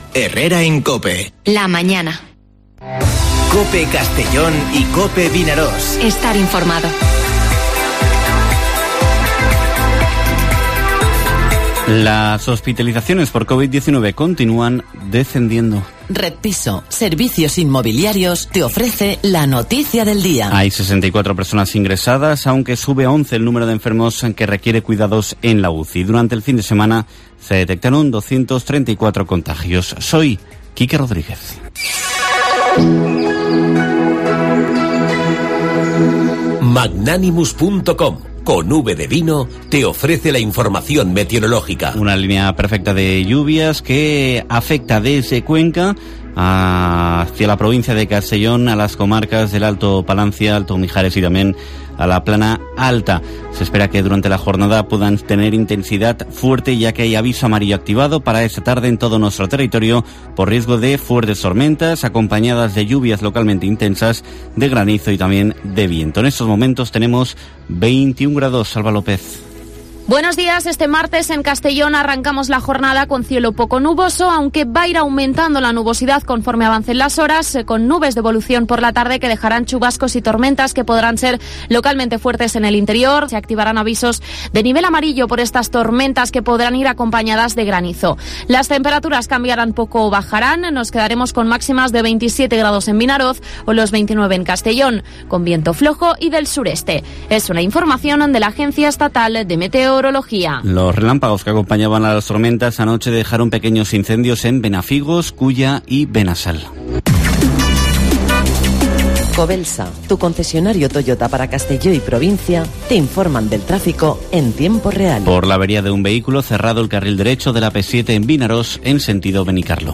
Informativo Herrera en COPE en la provincia de Castellón (24/08/2021)